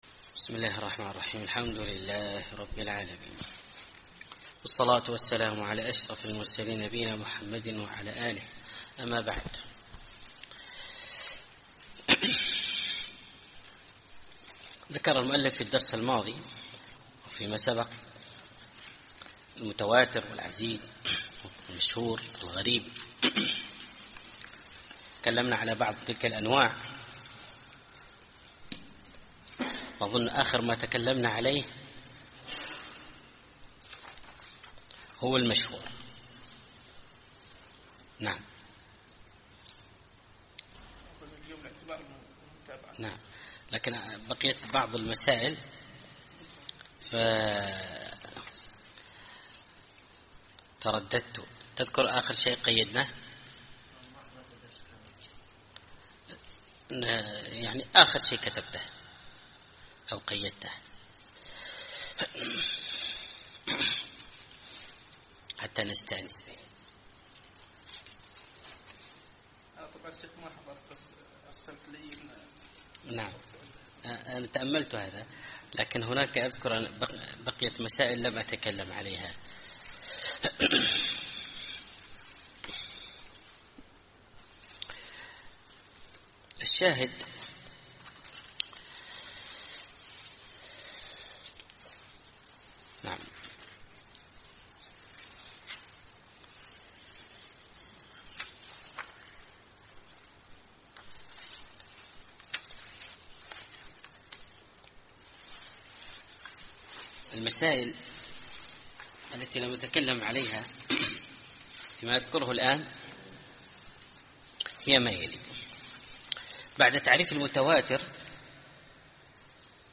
الدرس الحادي عشر